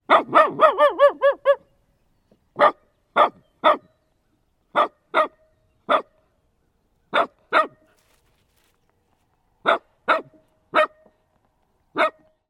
Звуки лая собак
Породистая собака лает (звук)